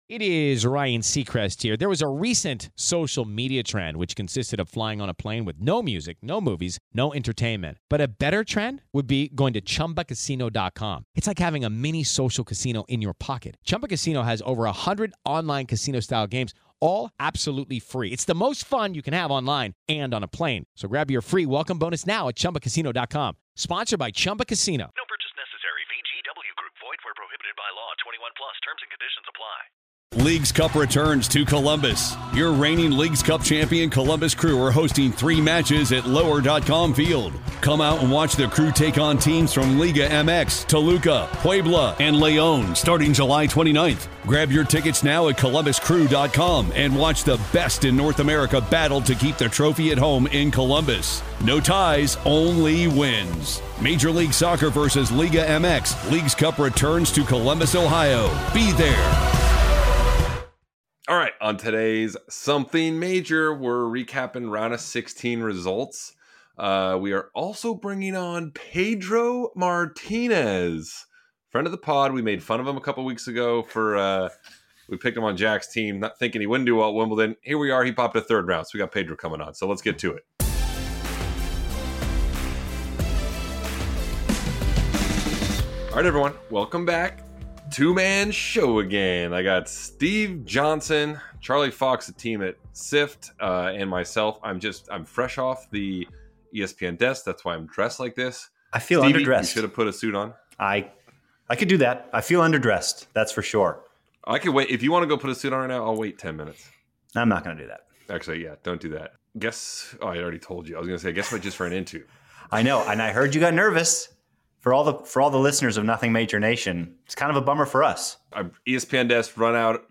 Special guest Pedro Martinez joins to discuss his surprise Wimbledon run, grass-court game, and the vibe inside the unseeded locker room. Predictions fly as the quarterfinals loom.
Interview